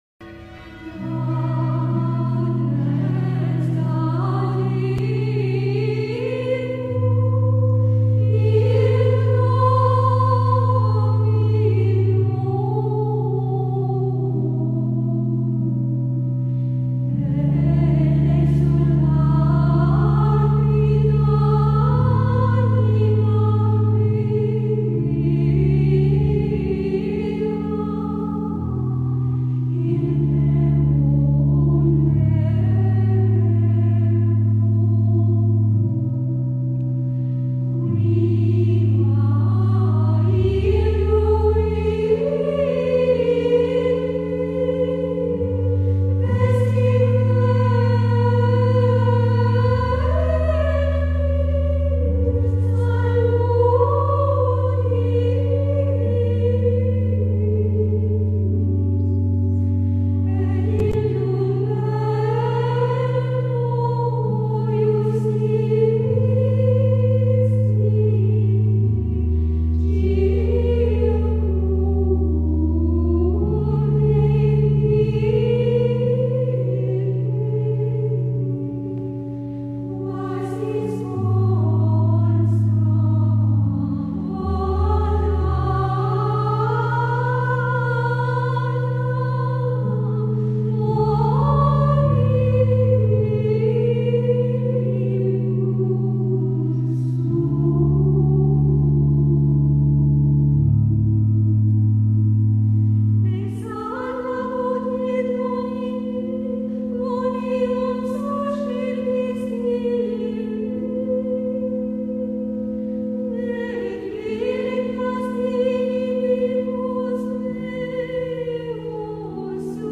Les antiennes Ô sont des chants de la liturgie catholique latine qui accompagnent le Magnificat aux vêpres des sept jours qui précèdent Noël. Elles sont ainsi nommées parce qu’elles commencent par l’interjection « ô » adressée au Christ.